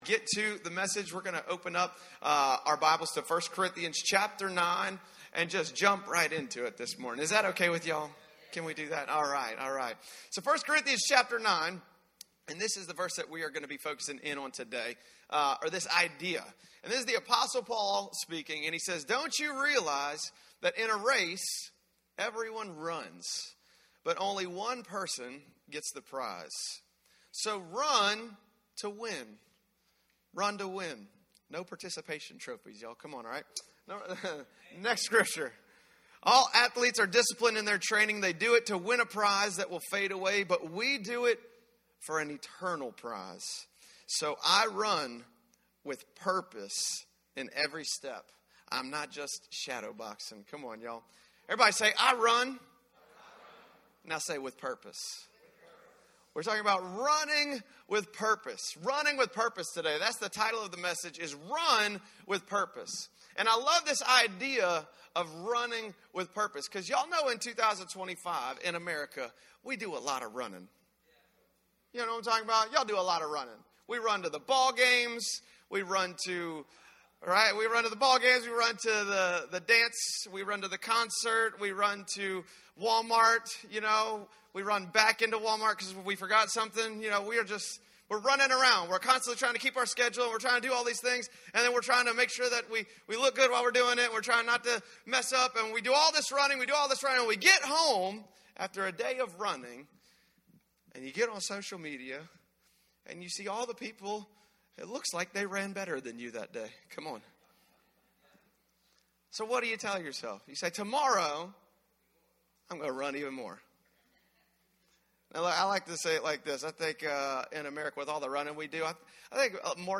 Subscribe to catch a new message each week from Victory Harvest Church in Baton Rouge, LA.